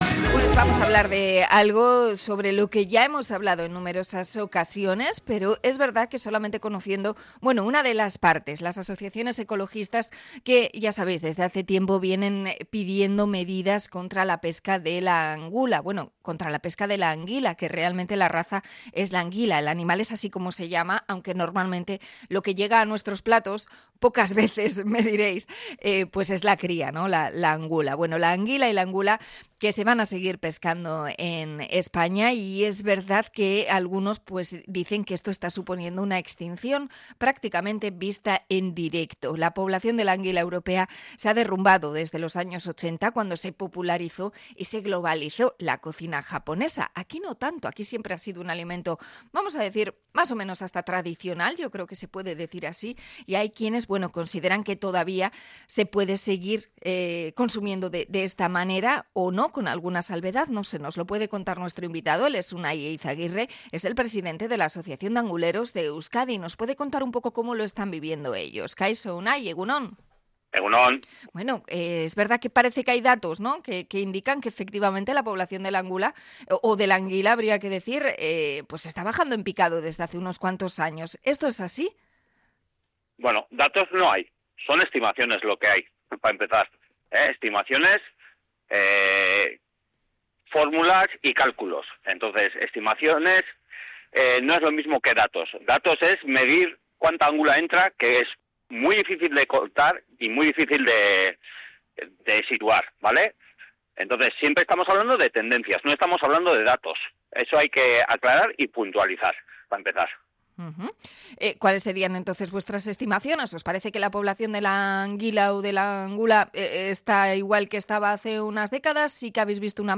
Entrevista a los anguleros de Euskadi sobre la población de la anguila